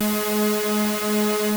KORG G#4 3.wav